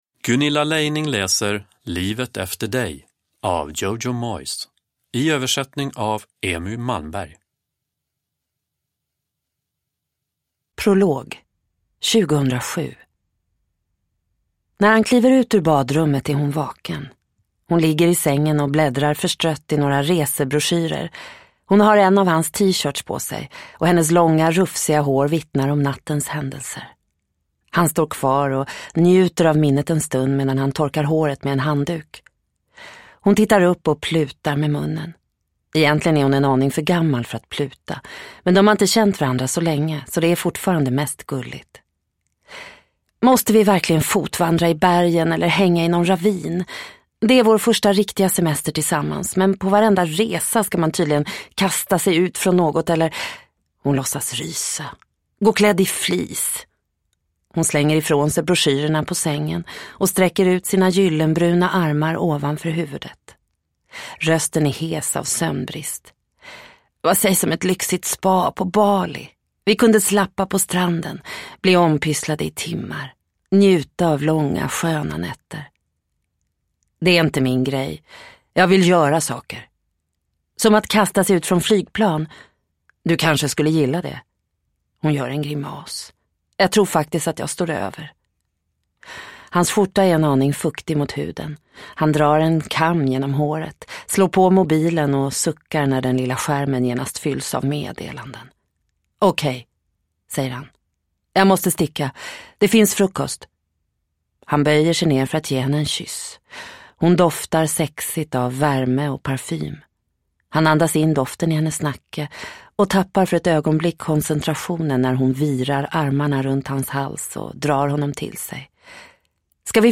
Livet efter dig – Ljudbok – Laddas ner